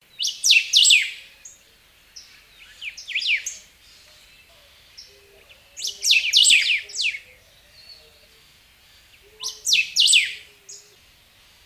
Golden-billed Saltator (Saltator aurantiirostris)
Life Stage: Adult
Location or protected area: Reserva Privada El Potrero de San Lorenzo, Gualeguaychú
Condition: Wild
Certainty: Observed, Recorded vocal